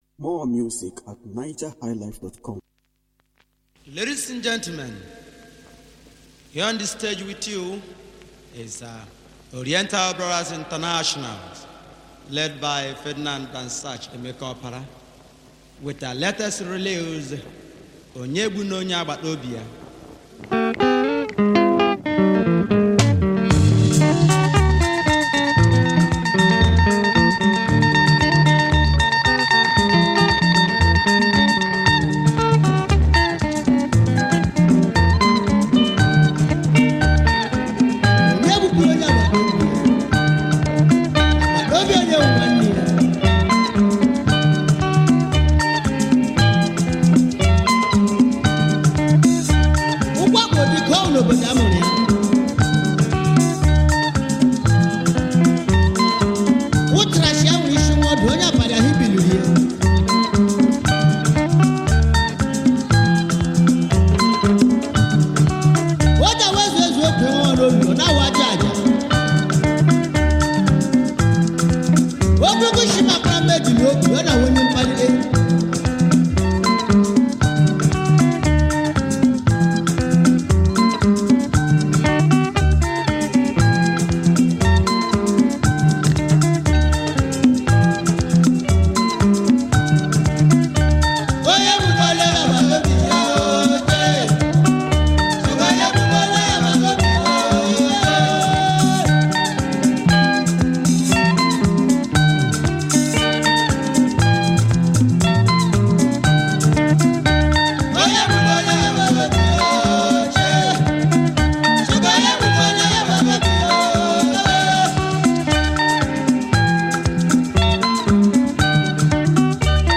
Nigeria most successful & popular highlife band